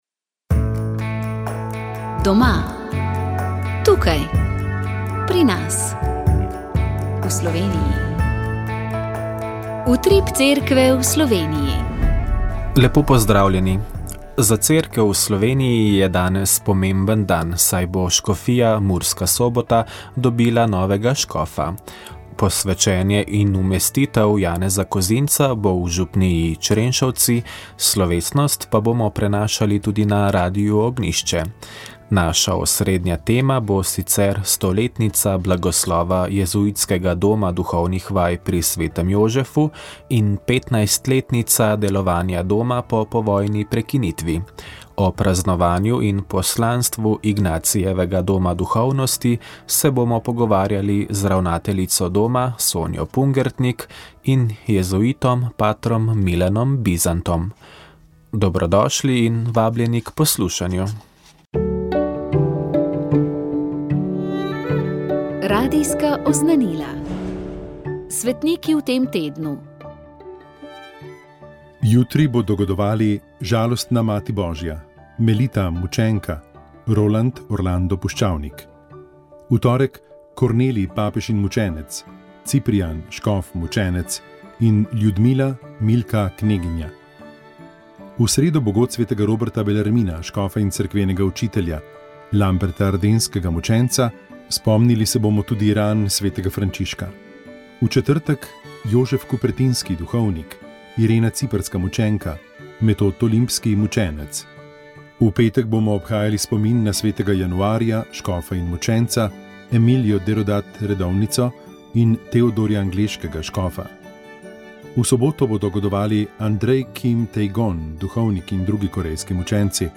V moški družbi smo odprli vprašanje o tem, kako se moški pogovarjamo o življenju, smislu, dvomih, strahovih, izgubah...